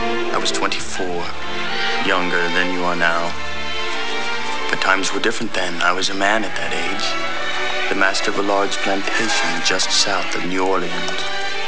Here is more of the conversation…